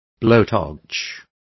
Complete with pronunciation of the translation of blowtorch.